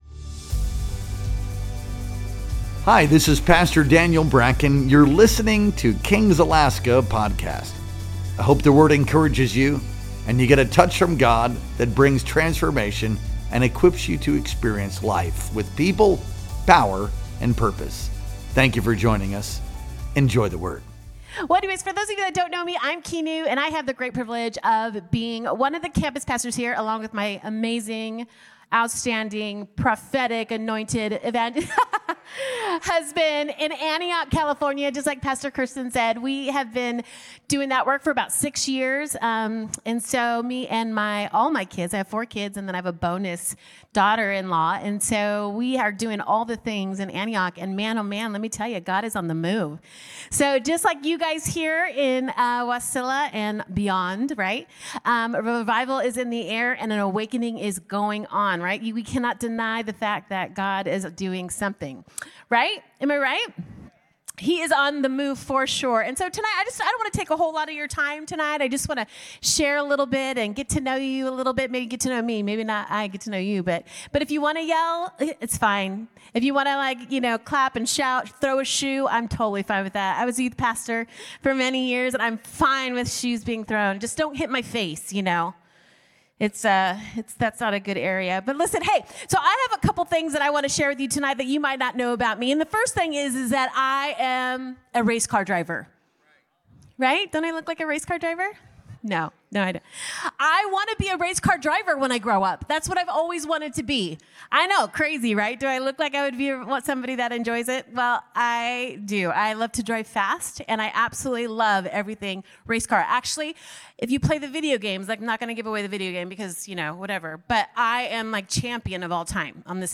Our Wednesday Night Worship Experience streamed live on November 12th, 2025.